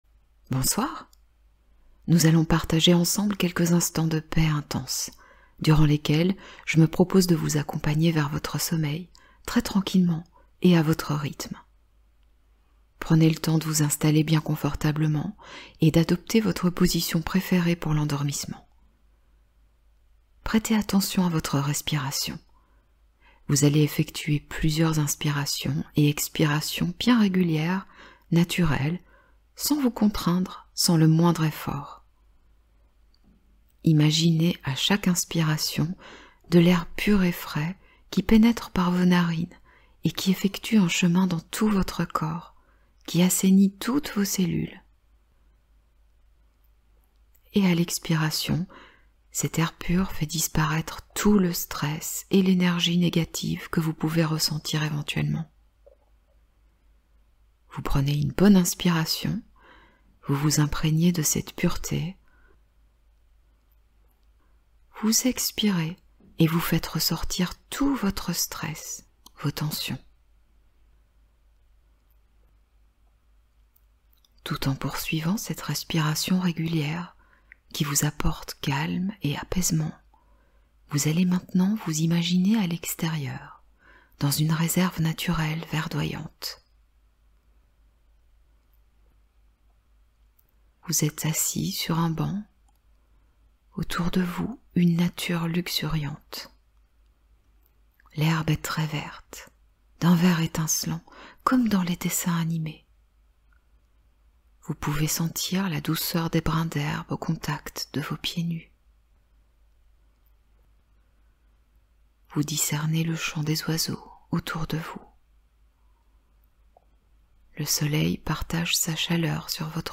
Hypnose de sommeil : soin énergétique pour apaiser l’esprit